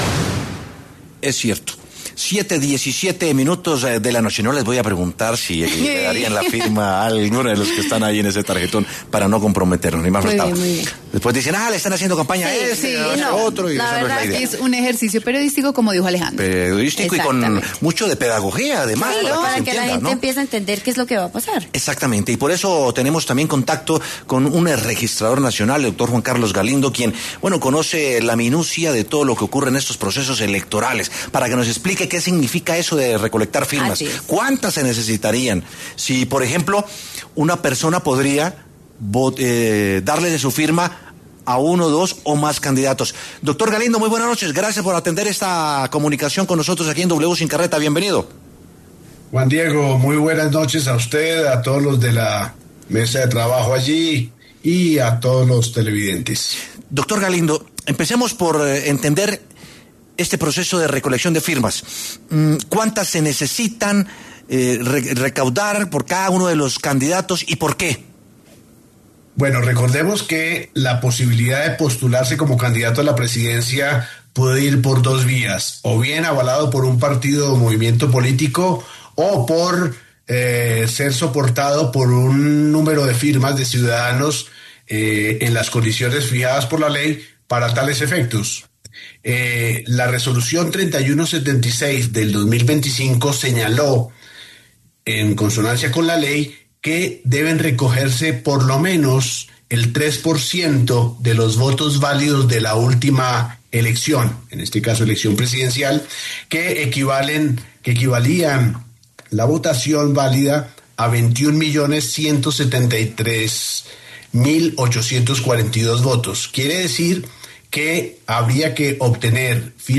El exregistrador general de la Nación, Juan Carlos Galindo, lo explicó en W Sin Carreta.